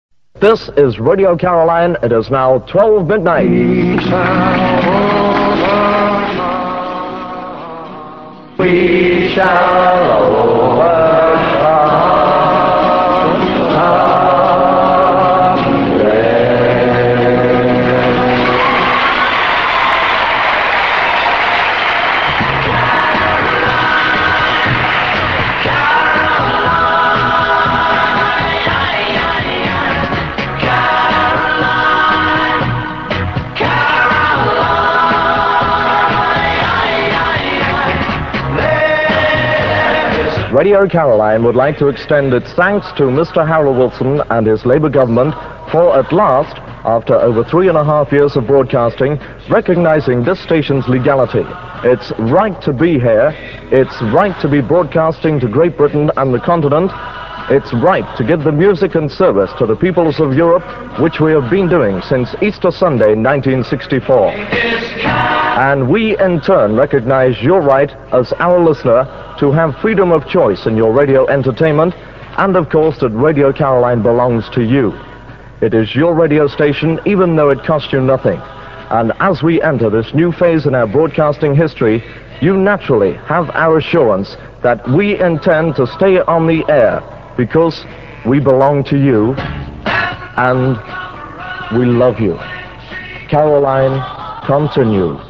click to hear audio Johnnie on Radio Caroline South at midnight on 14th August 1967 (duration 1 minute 35 seconds)